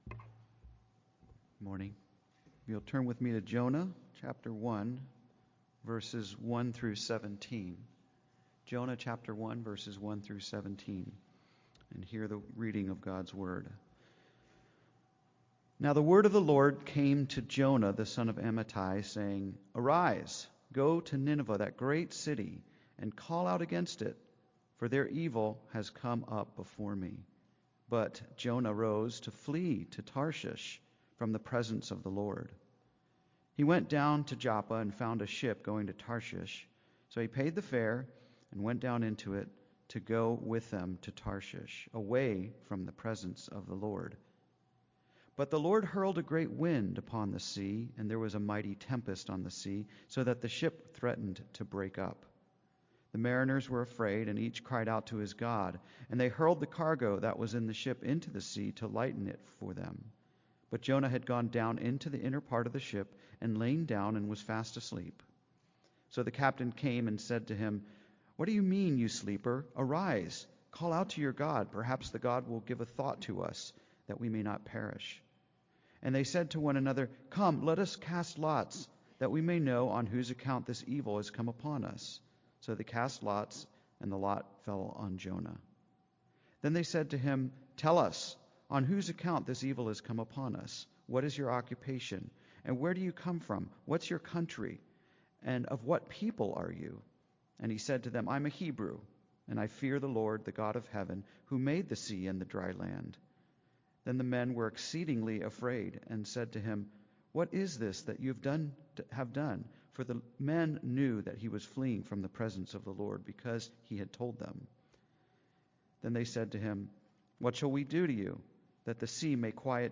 The Ups and Downs of Serving the Lord: Sermon on Jonah 1:1-17 - New Hope Presbyterian Church